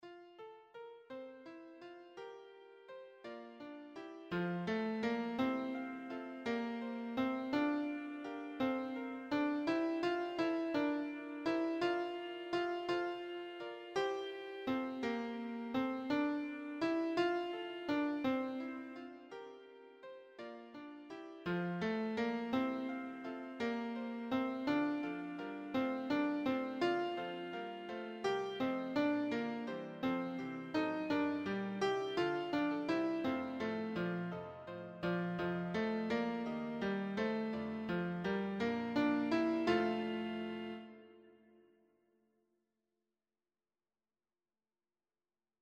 Brahms 0p. 74 Warum 2. Wenig bewegter